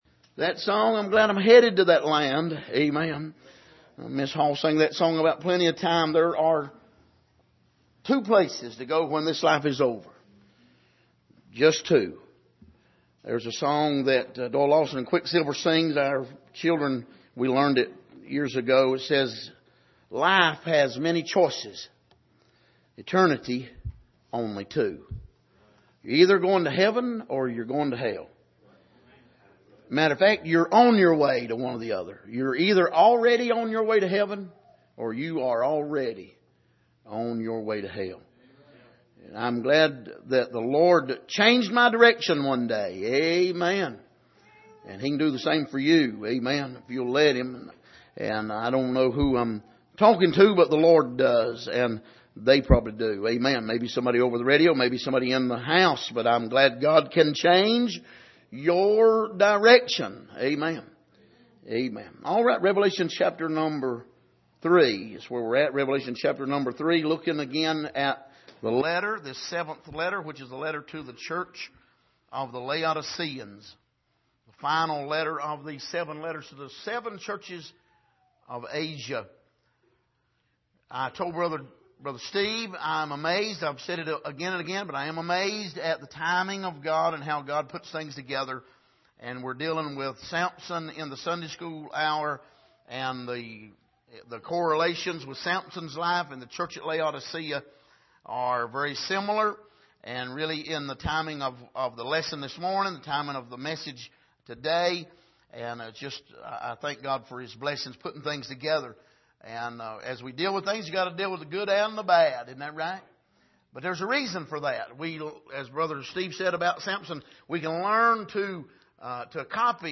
Passage: Revelation 3:14-22 Service: Sunday Morning